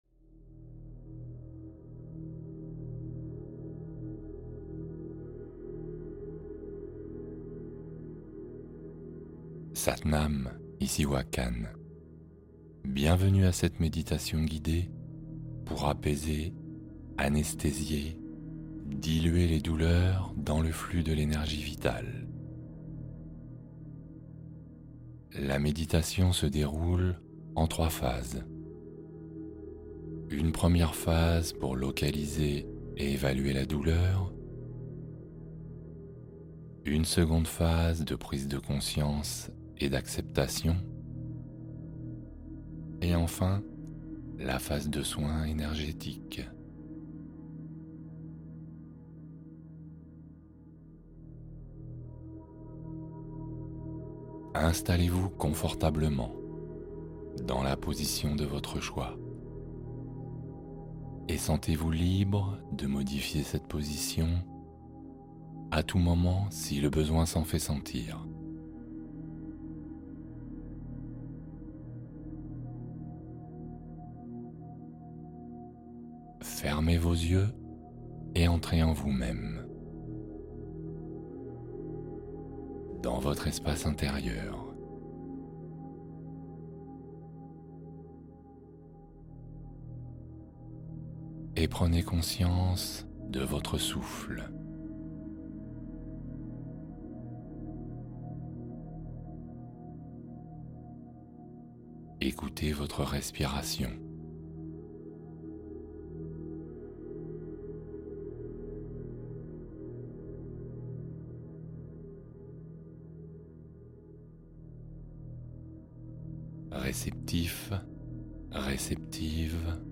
Soulagez la douleur sans médicaments - L'anesthésie naturelle par la méditation guidée